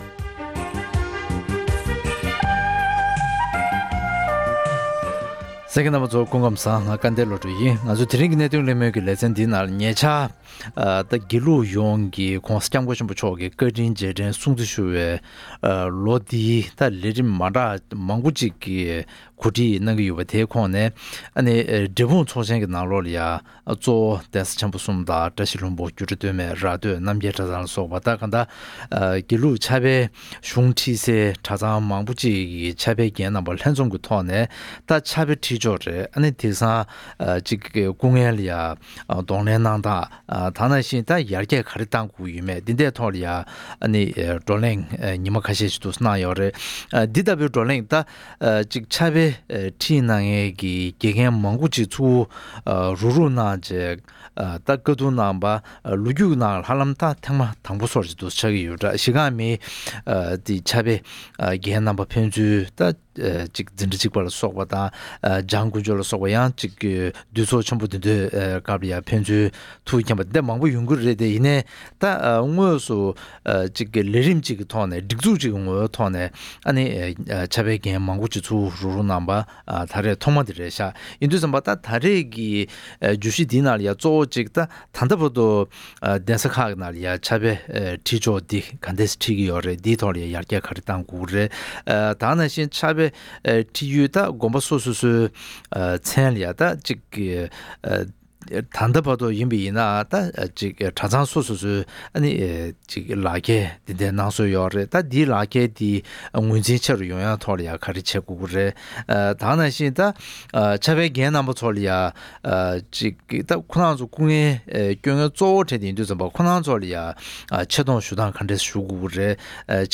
ཐེངས་འདིའི་གནད་དོན་གླེང་མོལ་གྱི་ལེ་ཚན་ནང་།